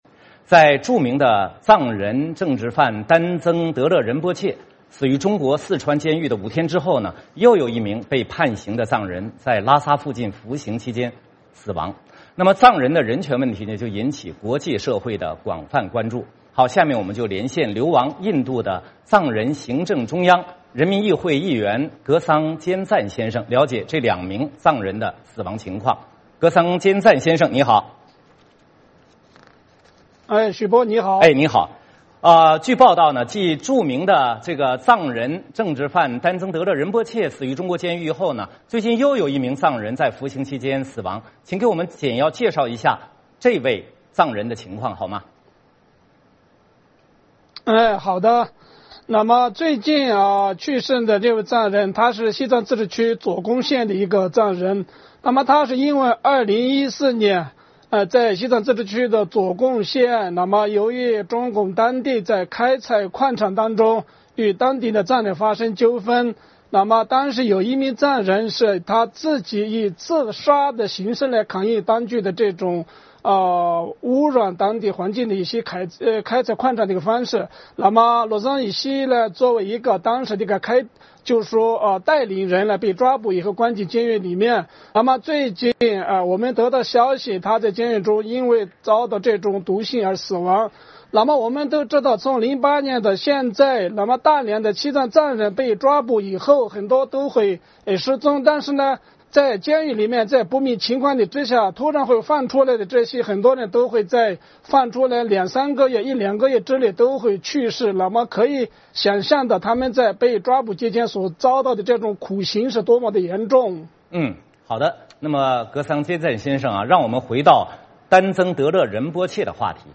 在著名藏人政治犯丹增德勒仁波切死于中国四川监狱5天之后，又有一名被判刑的藏人在拉萨附近服刑期间死亡，藏人的人权问题引起国际社会的广泛关注。我们连线流亡印度的藏人行政中央人民议会议员格桑坚赞先生，了解这两名藏人的死亡情况。